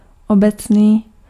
Ääntäminen
Vaihtoehtoiset kirjoitusmuodot (vanhahtava) generall (rikkinäinen englanti) gen'ral Synonyymit generic Ääntäminen US : IPA : /ˈdʒɛ.nə.ɹəl/ UK : IPA : /ˈdʒɛnɹəl/ US : IPA : /ˈdʒɛnɹəl/ Lyhenteet ja supistumat (laki) Gen.